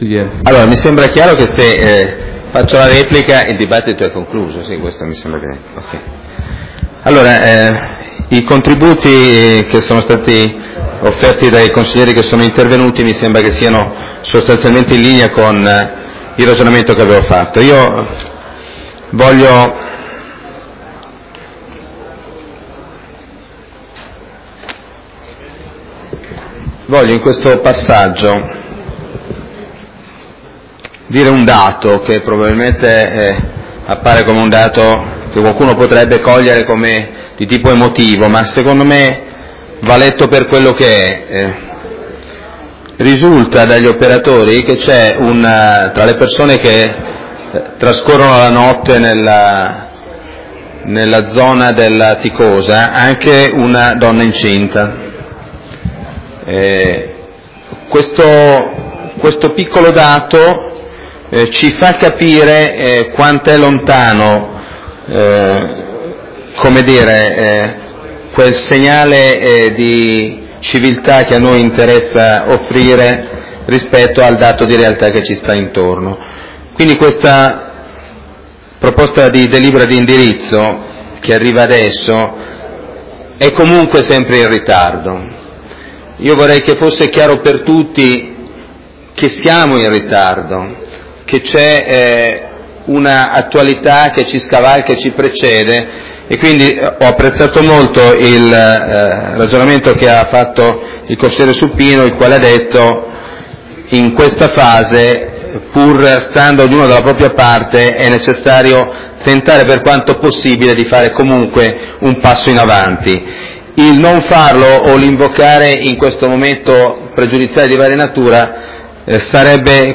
Riportiamo di seguito gli interventi audio dei consiglieri e dell'Assessore in occasione della bocciatura.
Podcast audio del Consiglio Comunale (mp3):